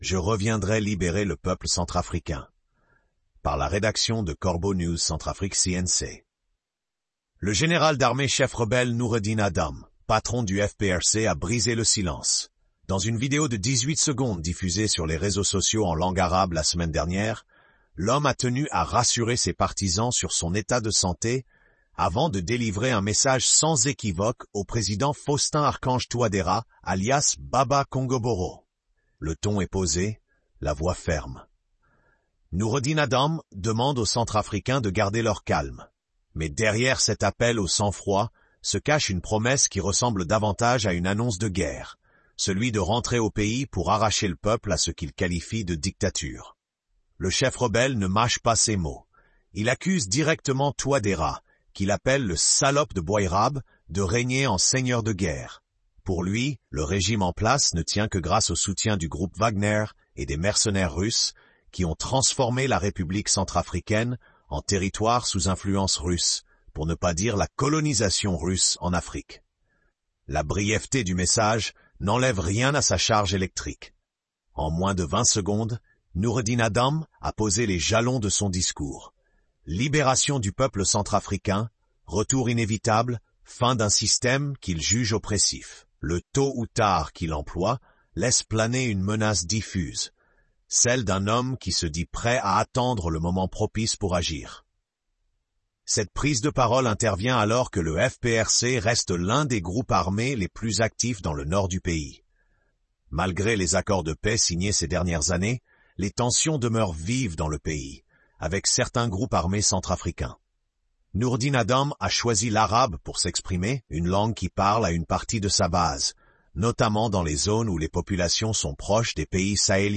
Le ton est posé, la voix ferme. Nourredine Adam demande aux Centrafricains de garder leur calme.